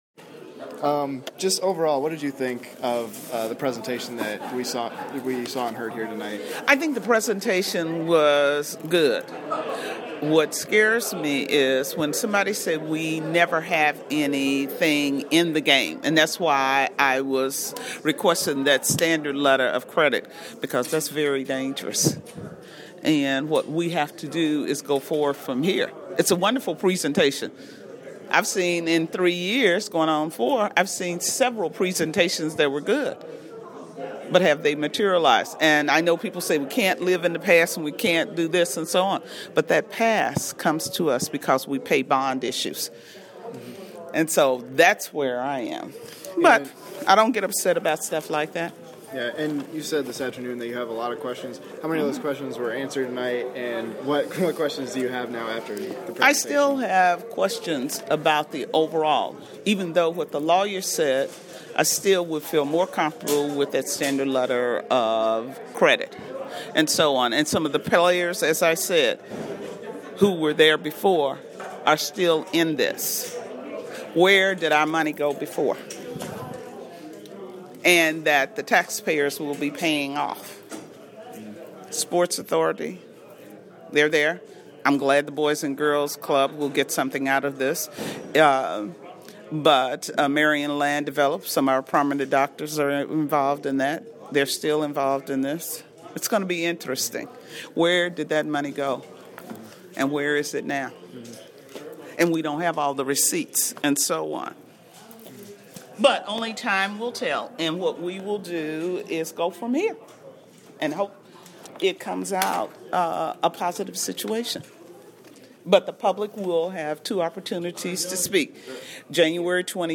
Council President Joselyn Whitticker gives her thoughts on the I-69 Indiana 18 development project.